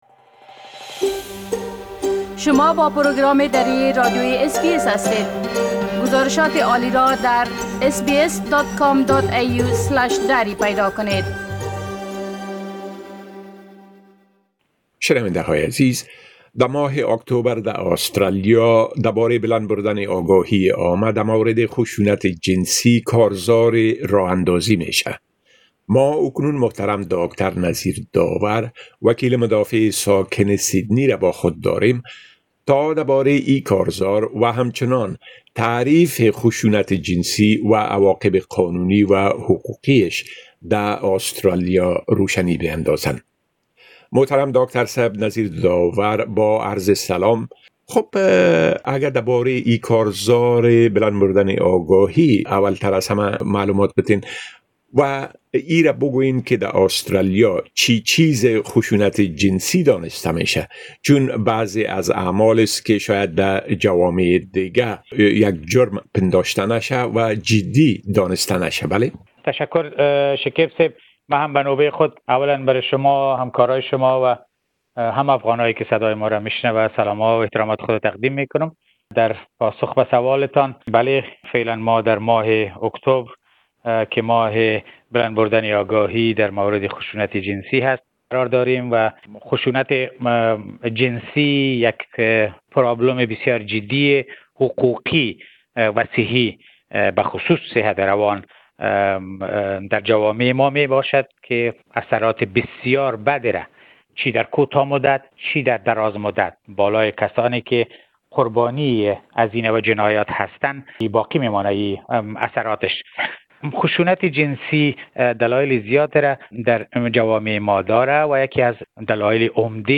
گفت‌وگوی اس‌بی‌اس دری